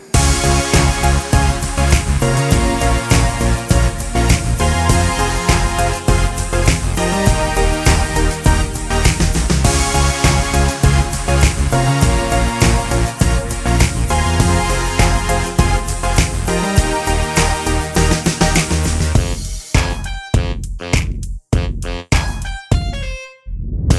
no Backing Vocals R'n'B / Hip Hop 3:23 Buy £1.50